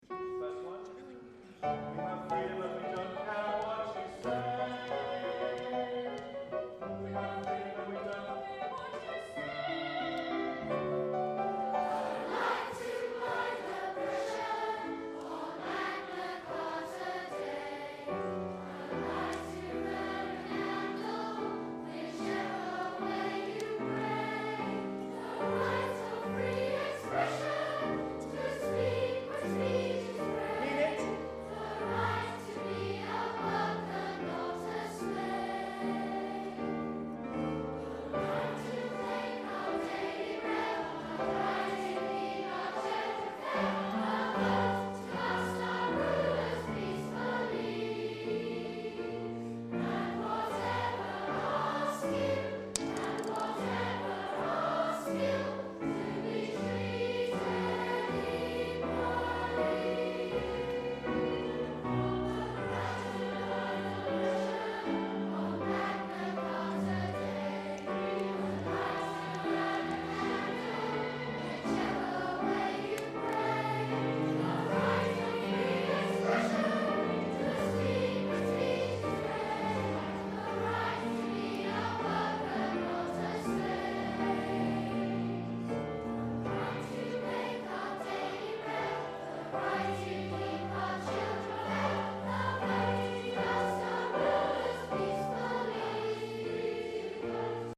Schoolchildren, youth groups and choirs have been busy rehearsing for a performance of The Freedom Game at at the Royal Albert Hall next Tuesday (12 May) in front of Prince Edward and Sophie, Countess of Wessex.
In the video and audio clips below, singers rehearse The Eight Freedoms.